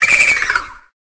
Cri de Froussardine dans Pokémon Épée et Bouclier.